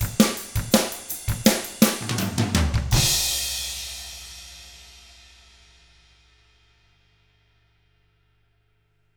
164ROCK E1-L.wav